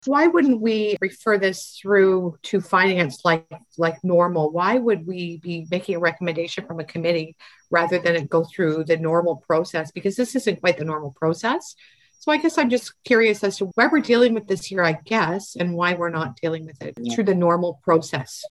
Belleville's Economic and Destination Development Committee meets virtually, on February 25, 2021.
Councillor Kelly McCaw told the committee while she didn’t object to the hiring, she didn’t feel the right process was being followed.